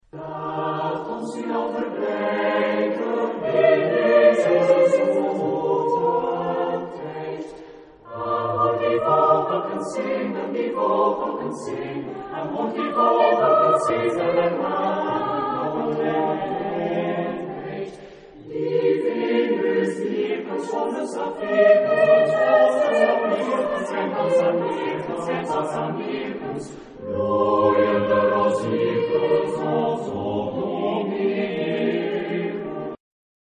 Genre-Style-Forme : Chanson ; Renaissance
Type de choeur : SATB  (4 voix mixtes )
Tonalité : fa majeur